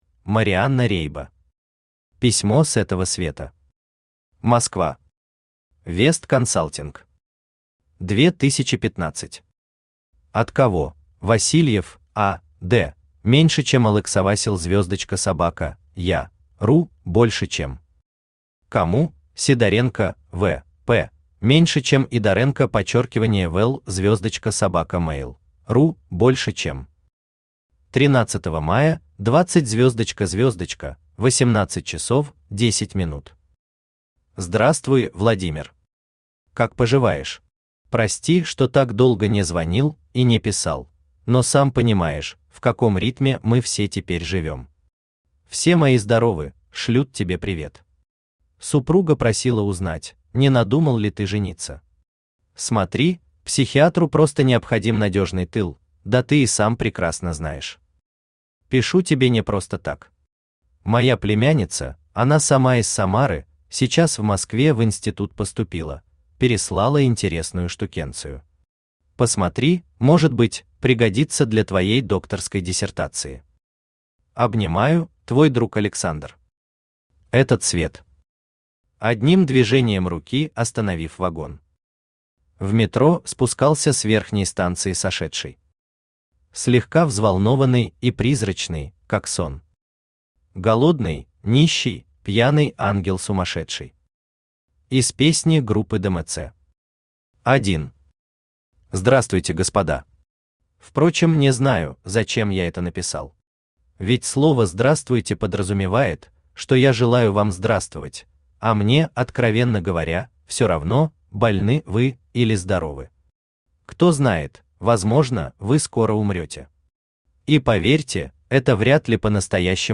Аудиокнига Письмо с этого света | Библиотека аудиокниг
Aудиокнига Письмо с этого света Автор Марианна Рейбо Читает аудиокнигу Авточтец ЛитРес.